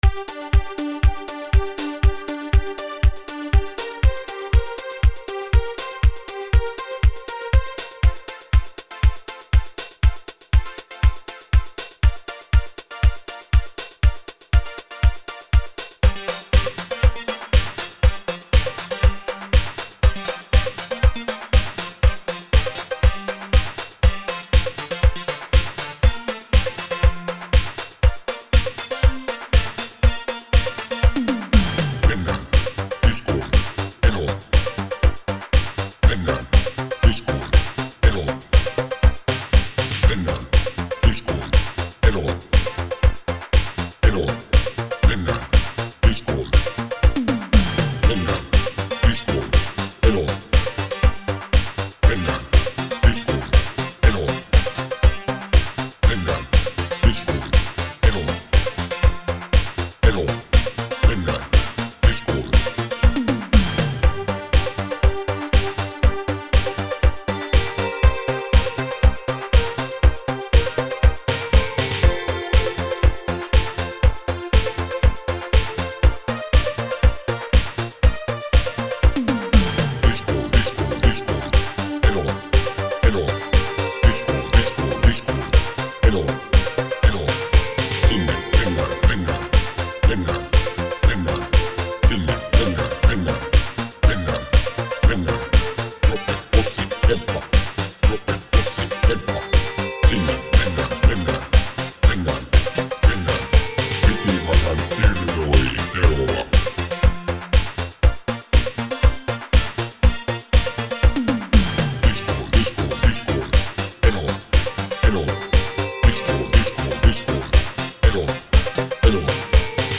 80-luvun italodiskotyylisen coverin